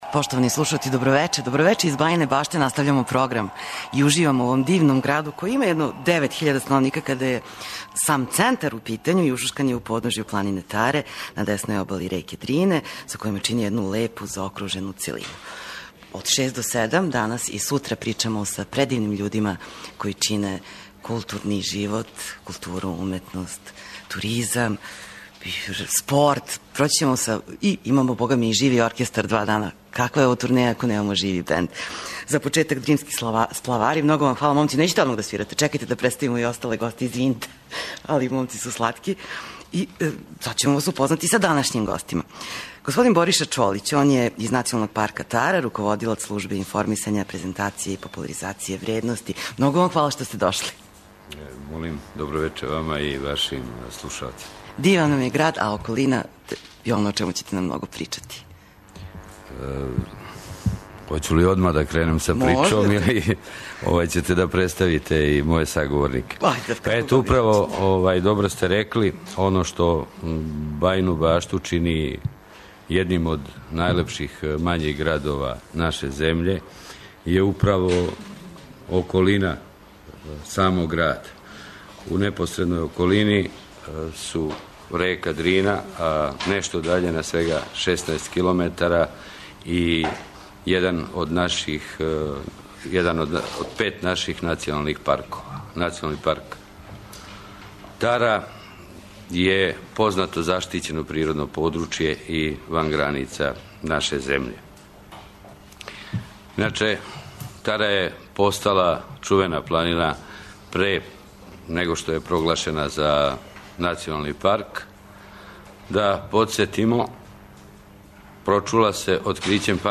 Емисију емитујемо уживо из Бајине Баште, представићемо вам културни живот овога града.